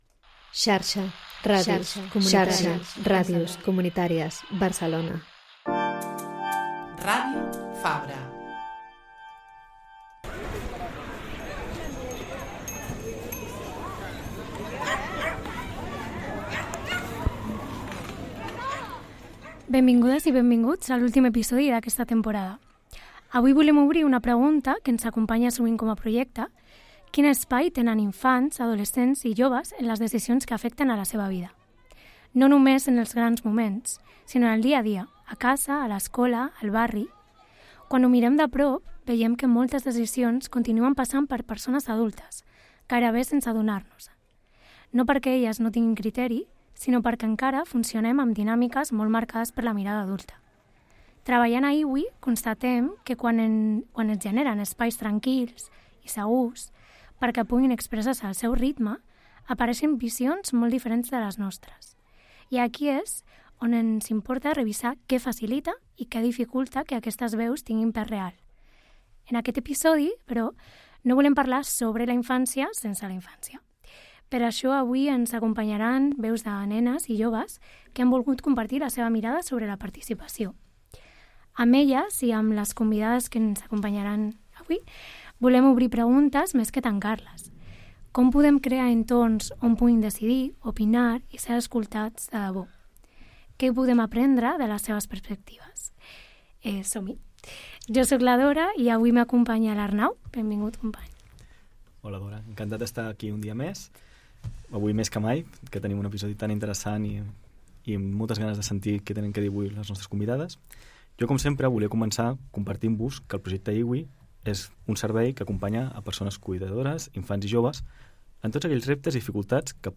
Per això, hem convidat veus de nenes i joves que han volgut compartir la seva mirada sobre la participació.